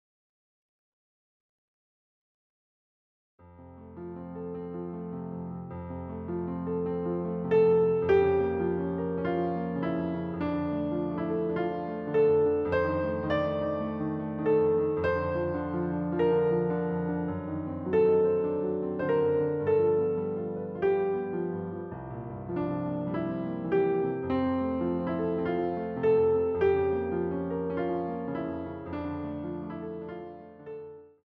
using the stereo sa1mpled sound of a Yamaha Grand Piano.